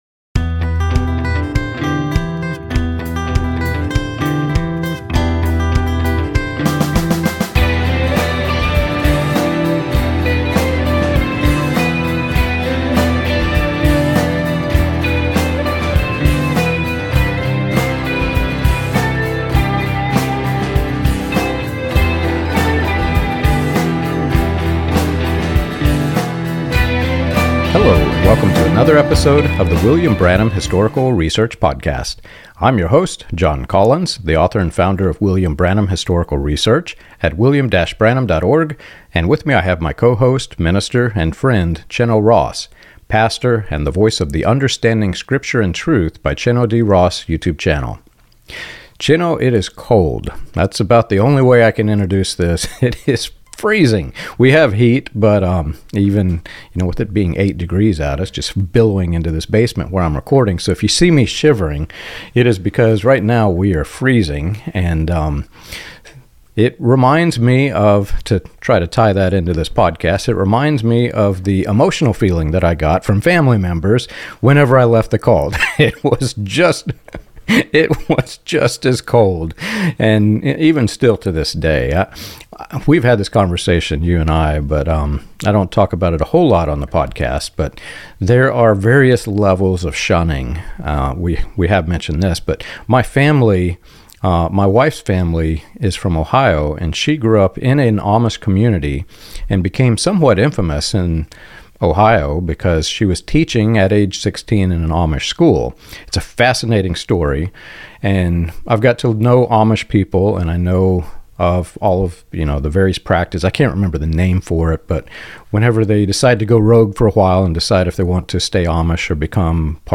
The conversation examines spiritual pride, fear-based control, and the harm caused when leaders discourage medical treatment, using real examples involving cancer, rapture anxiety, and family division.